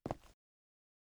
Illusion-UE5/Concrete Walk - 0004 - Audio - Stone 04.ogg at 8a16a189dfa873bd7eabf0d9ce5a00fecc7d4d4f